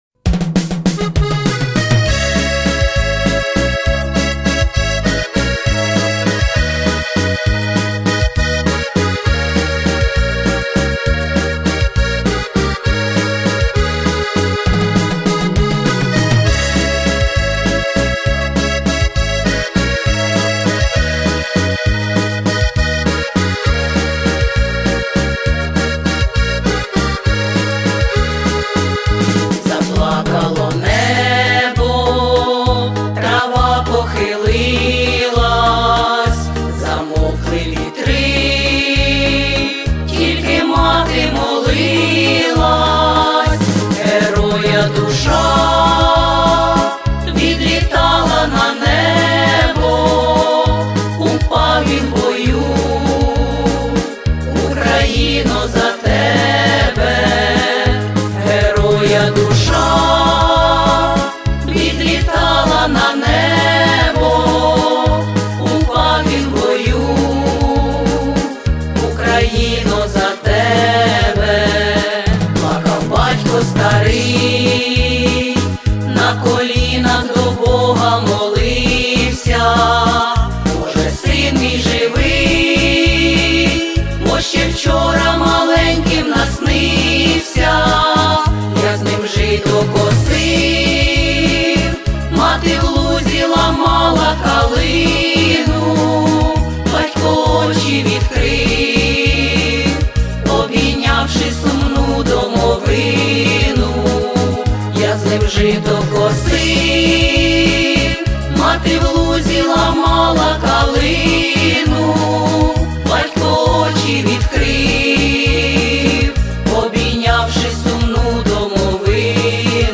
Всі мінусовки жанру Pop-Folk
Плюсовий запис